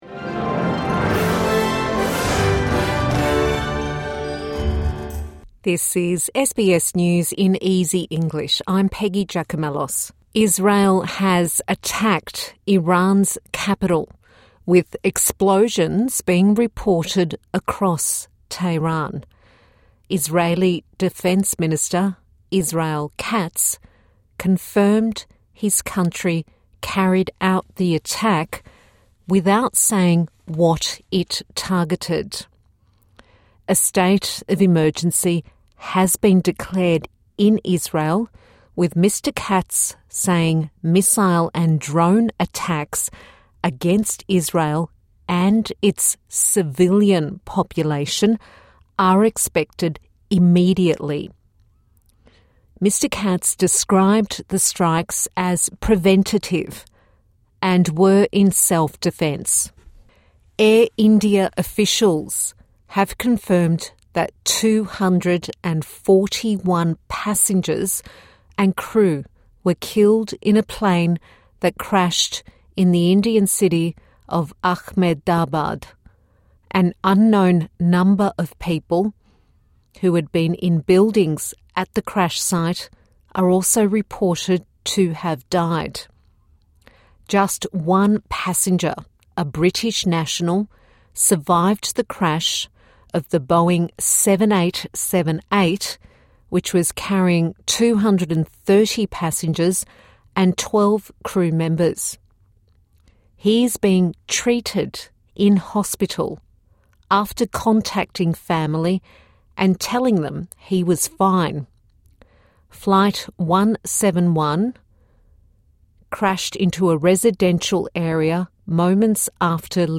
A five minute news bulletin for English language learners